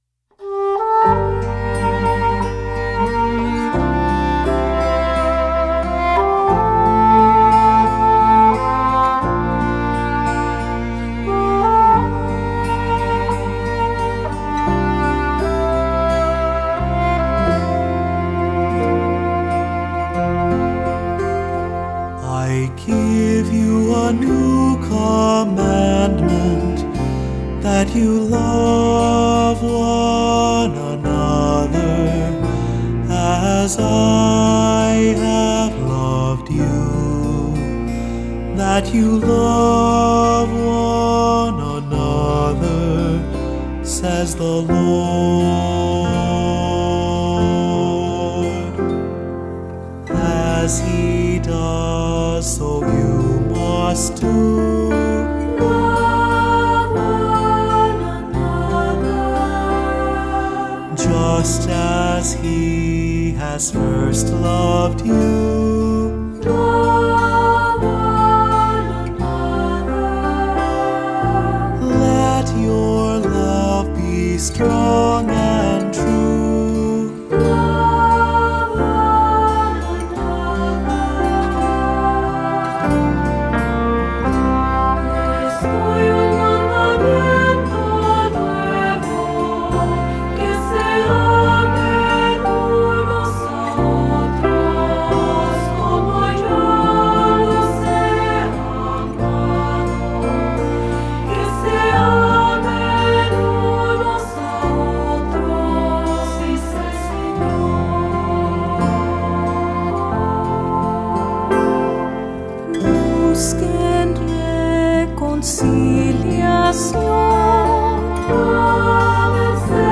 Voicing: Three-part mixed; Cantor; Assembly